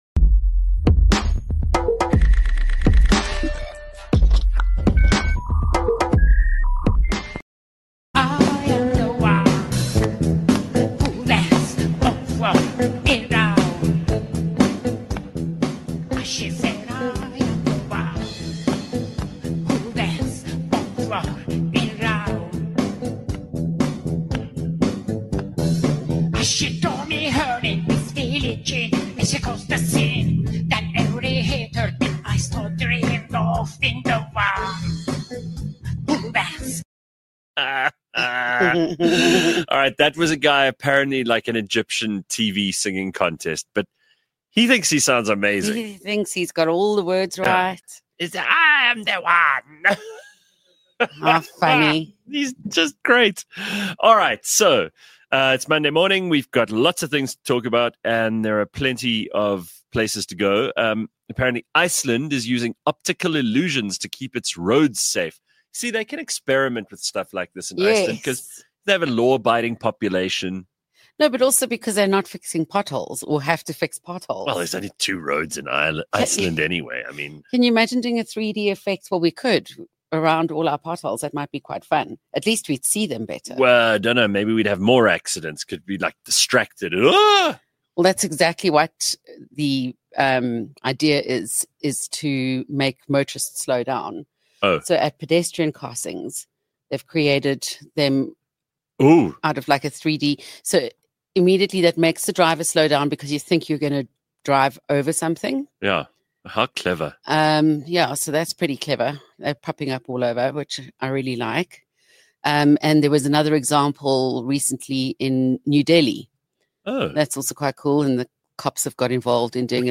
A hybrid of online radio, podcast and YouTube – live from 06h00 to 08h00 – Gareth and his team bring you ‘unradio’ – unscripted, uncensored, real conversations about everything that happens in our world, everything we all experience every day, what makes us think… makes us laugh… makes us cry… makes us angry… inspires us… and makes us human.